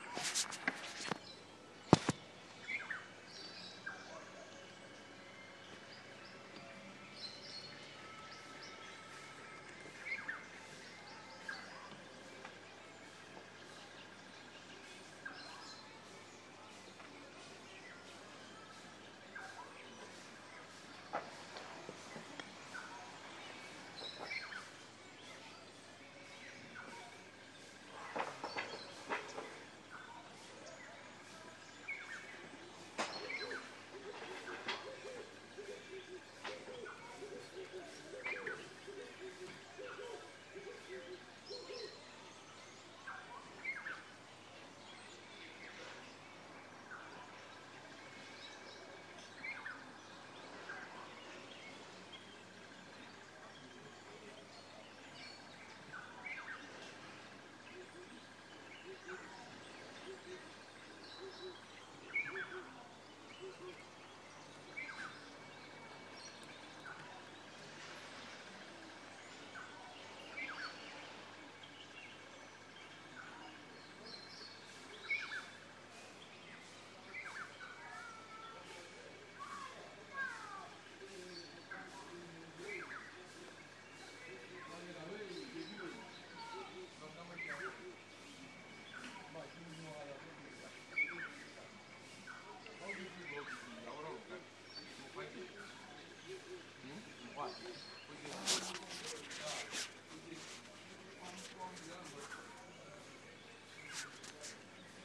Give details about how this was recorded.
Morning greeting at the Lodge house at Tachila, of of a number of properties on the reserve.